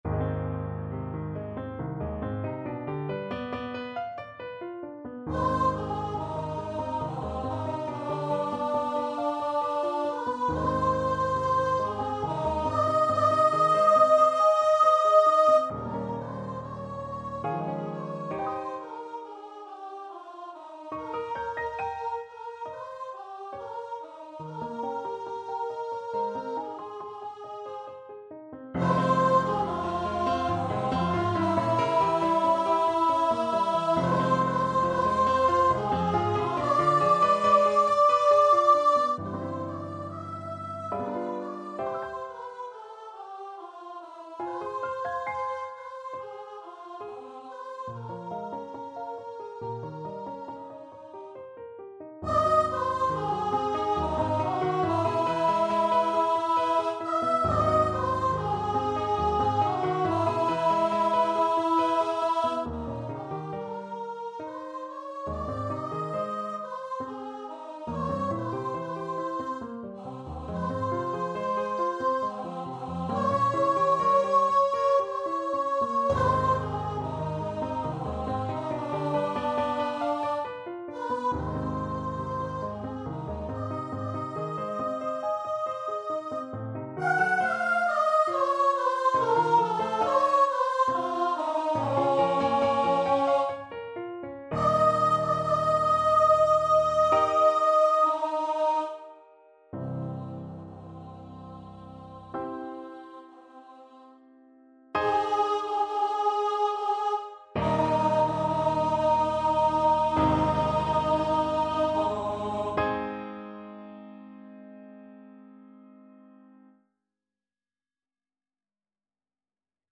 Voice
Ab major (Sounding Pitch) (View more Ab major Music for Voice )
~ = 69 Large, soutenu
3/4 (View more 3/4 Music)
Classical (View more Classical Voice Music)